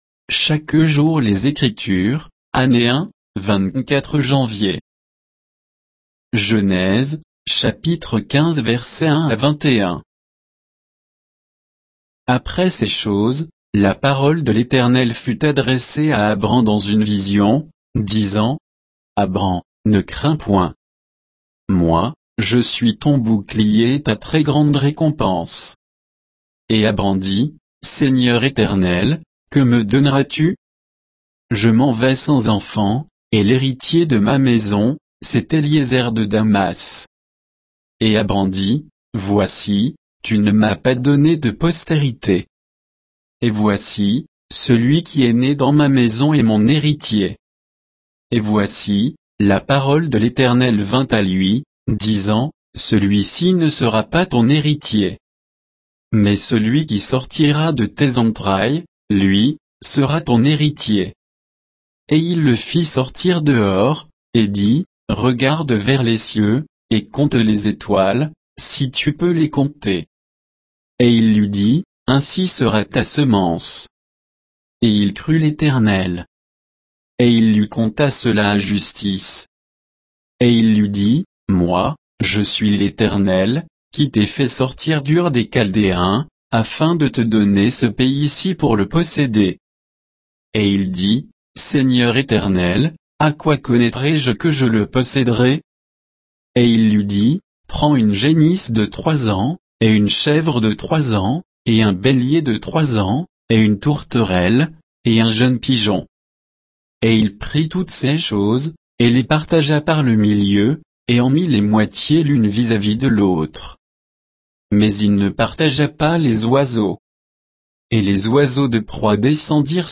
Méditation quoditienne de Chaque jour les Écritures sur Genèse 15, 1 à 21